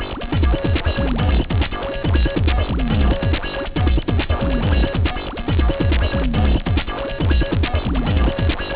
Muchos noise.